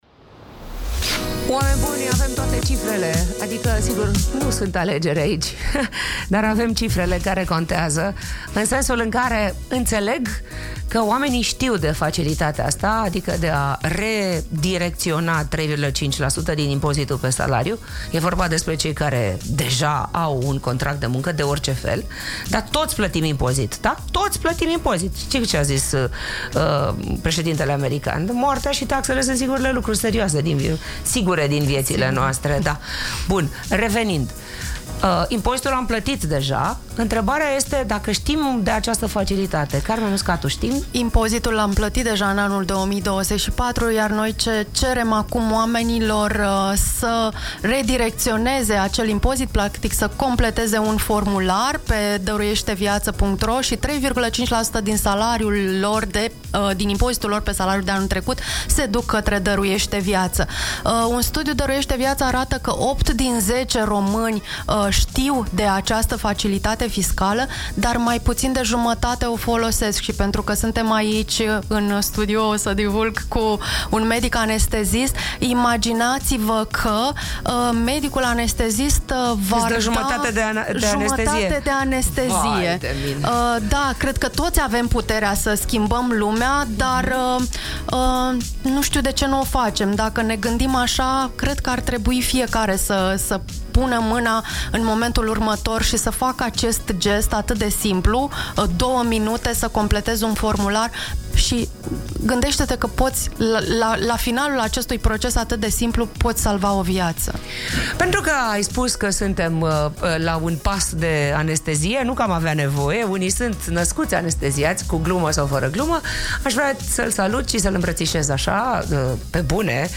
în direct din studioul Dăruiește viață